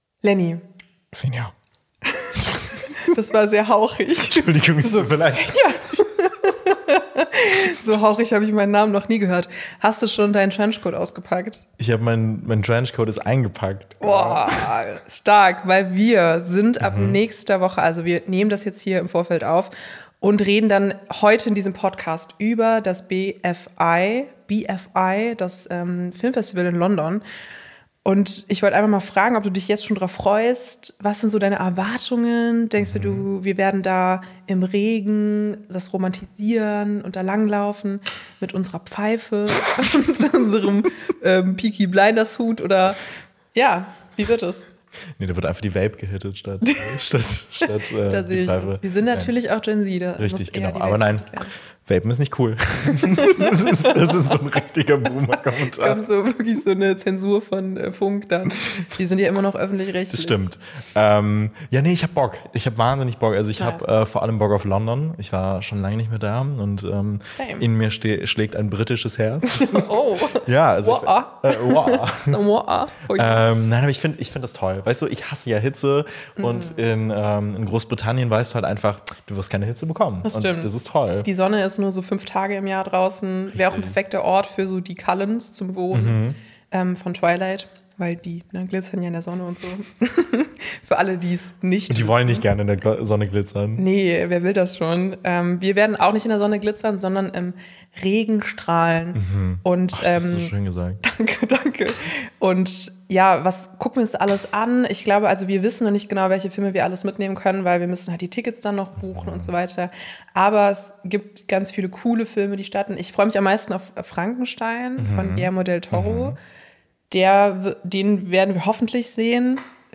Darüber berichten sie im Mittelteil des Podcasts von ihrem Hotelzimmer in England aus!